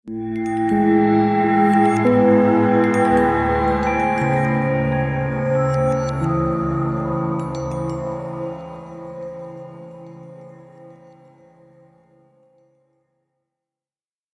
دانلود آهنگ باد 2 از افکت صوتی طبیعت و محیط
دانلود صدای باد 2 از ساعد نیوز با لینک مستقیم و کیفیت بالا
جلوه های صوتی